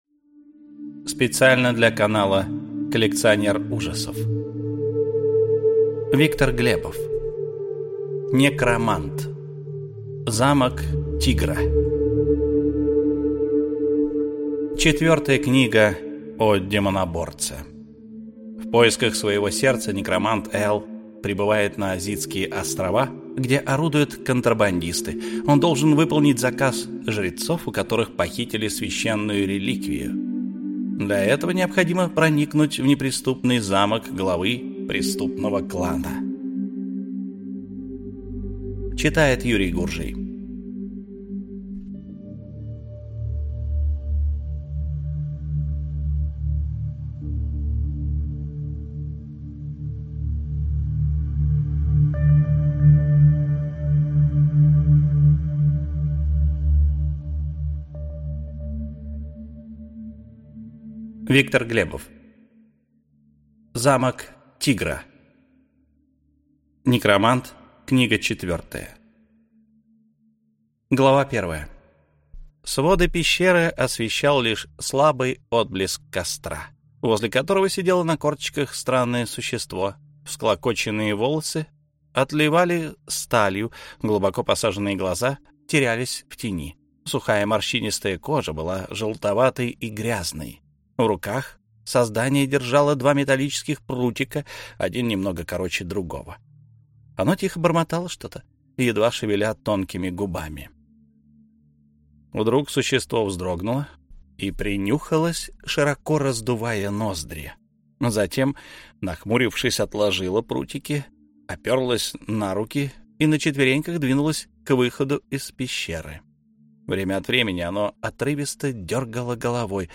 Аудиокнига Некромант: Замок тигра | Библиотека аудиокниг